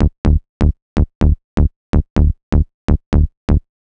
cch_bass_loop_kiss_125_Dm.wav